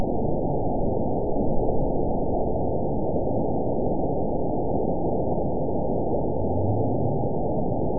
event 911258 date 02/19/22 time 08:08:47 GMT (3 years, 3 months ago) score 8.68 location TSS-AB01 detected by nrw target species NRW annotations +NRW Spectrogram: Frequency (kHz) vs. Time (s) audio not available .wav